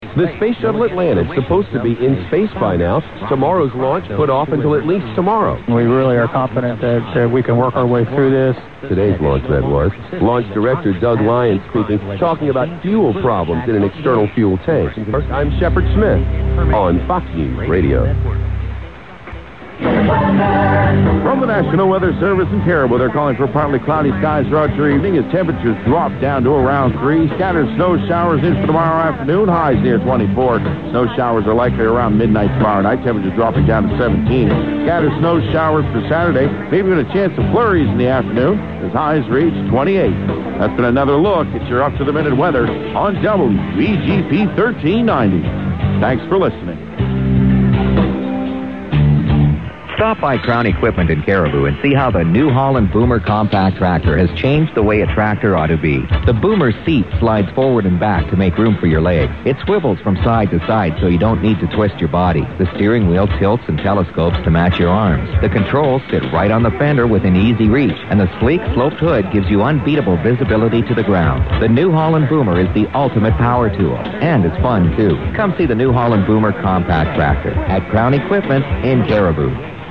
AUDIO CHECKS AGAINST OTHER RECEIVERS
Prob HF225 Europa.